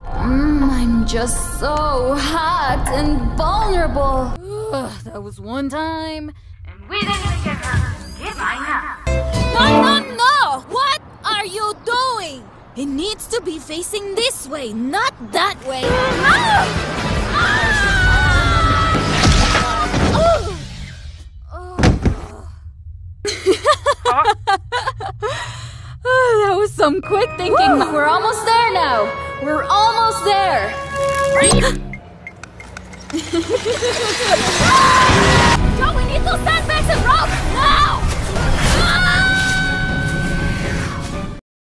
voice-over
Jong, Natuurlijk, Vriendelijk